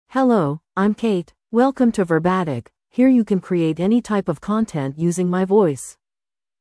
Kate — Female English (United States) AI Voice | TTS, Voice Cloning & Video | Verbatik AI
FemaleEnglish (United States)
Voice sample
Female
Kate delivers clear pronunciation with authentic United States English intonation, making your content sound professionally produced.